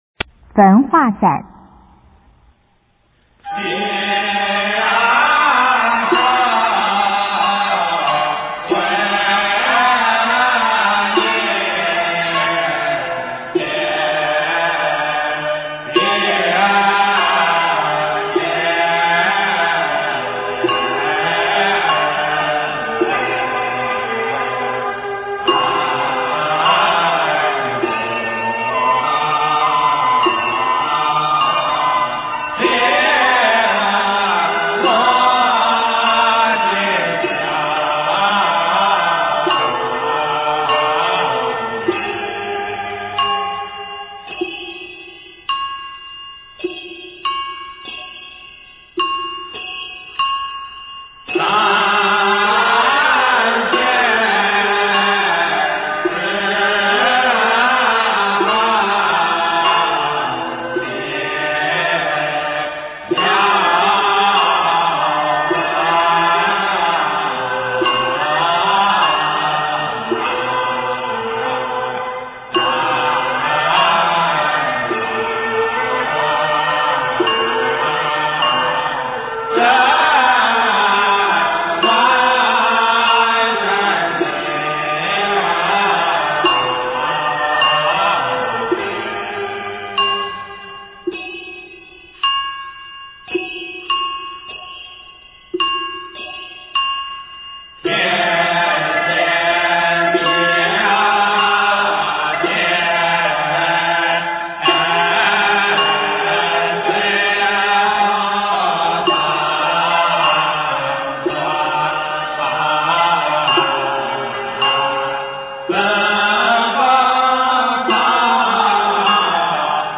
中国道教音乐 全真正韵 焚化赞
简介：焚化赞为道教科仪中送表、化表时所用，表示仰仗神威，遣发功曹、使者传递表奏，呈现刚健有力之美；而表文既送，心意亦达，给信士带来一种愿望已达的幸福感 。